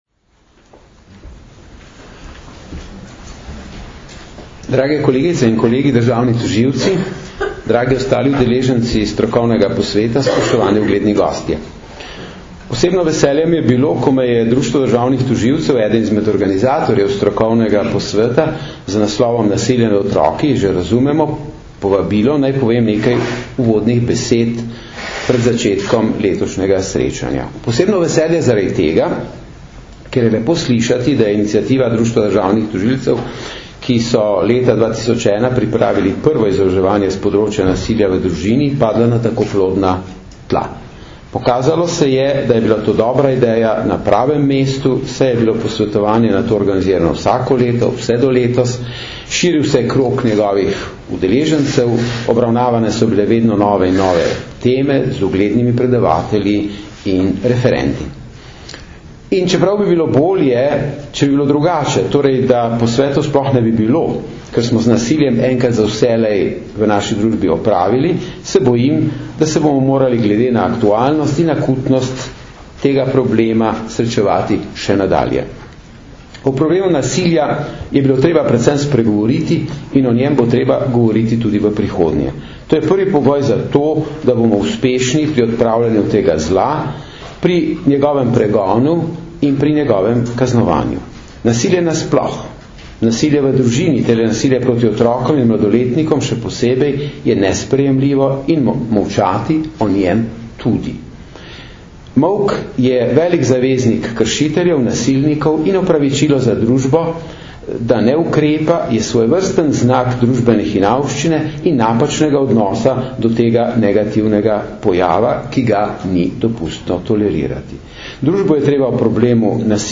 Na Brdu pri Kranju se je danes, 12. aprila 2012, začel dvodnevni strokovni posvet "Nasilje nad otroki – že razumemo?", ki ga letos že enajstič organizirata Generalna policijska uprava in Društvo državnih tožilcev Slovenije v sodelovanju s Centrom za izobraževanje v pravosodju.
Zvočni posnetek nagovora generalnega državnega tožilca dr. Zvonka Fišerja (mp3)